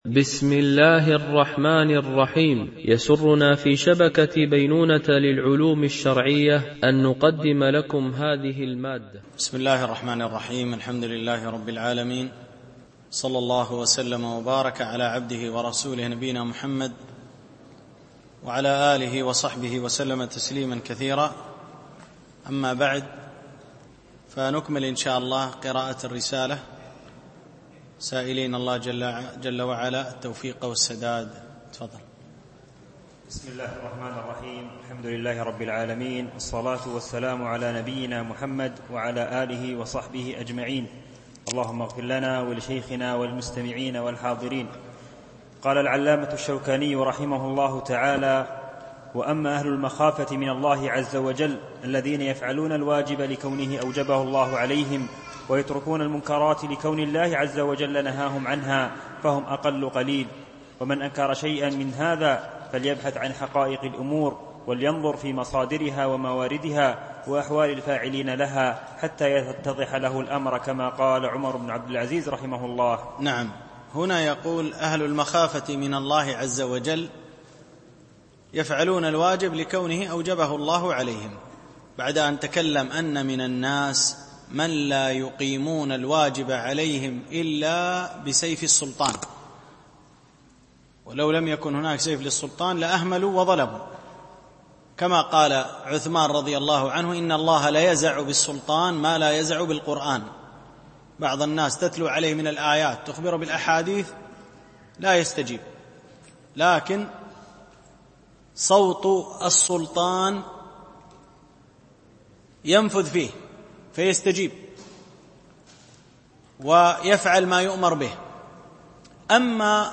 شرح رفع الأساطين في حكم الاتصال بالسلاطين - الدرس 3